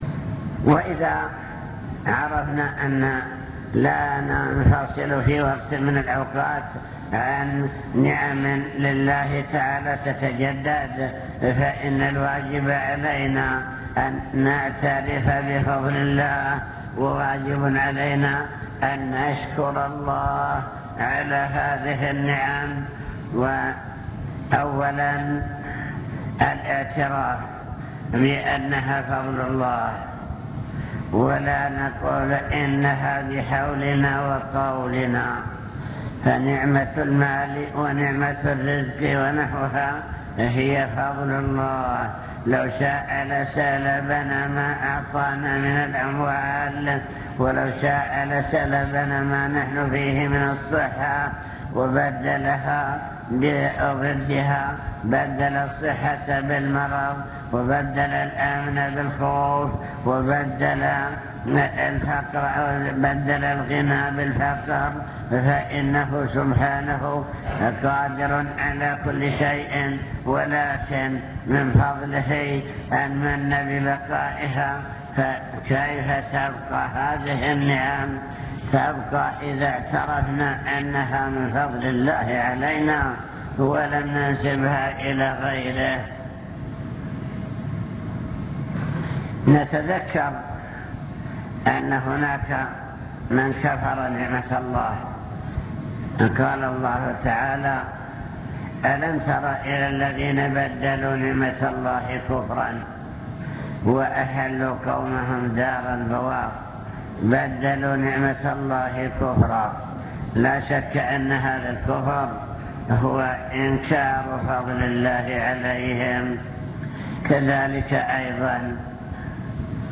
المكتبة الصوتية  تسجيلات - محاضرات ودروس  محاضرة بعنوان شكر النعم (2) واجب الإنسان نحو النعم